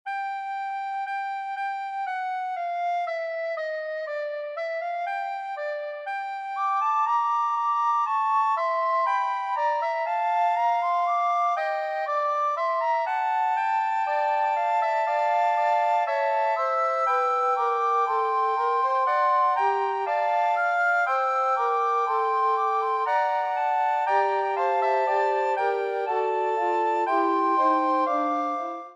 S A T B